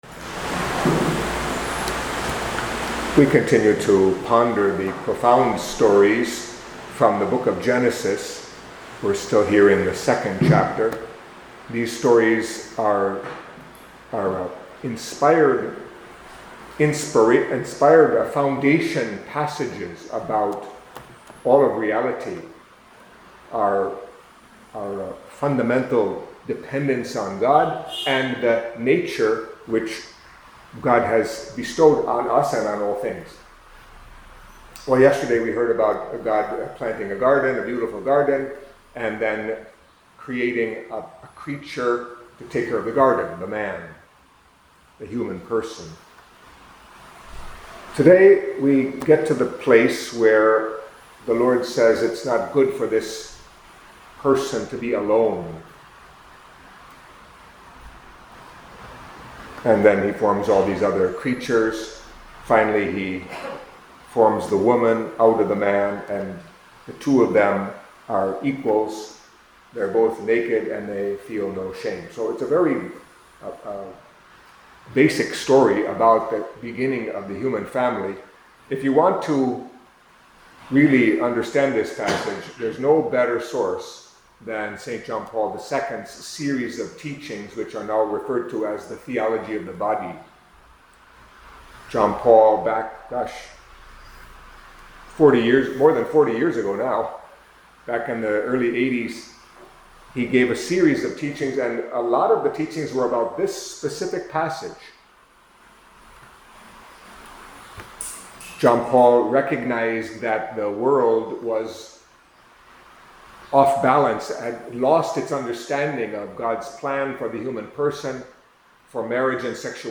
Catholic Mass homily for Thursday of the Fifth Week of Ordinary Time